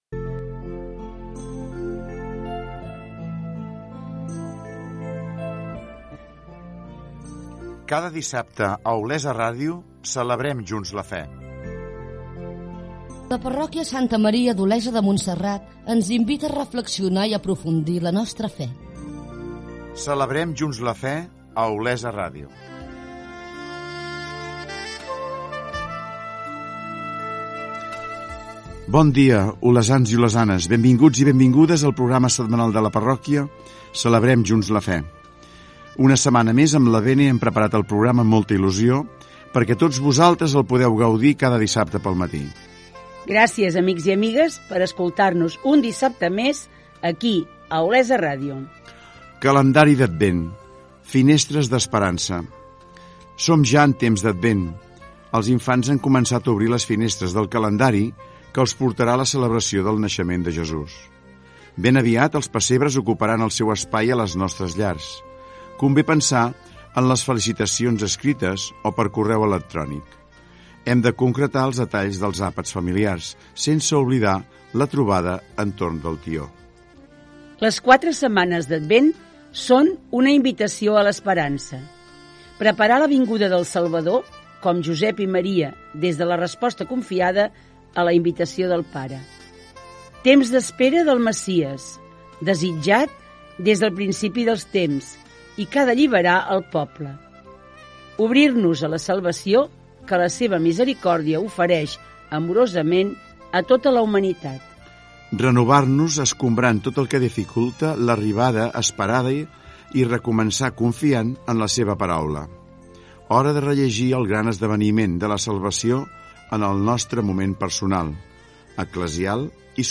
Careta, presentació del programa, el calendari d'Advent
Programa setmanal de la parròquia d'Olesa de Montserrat.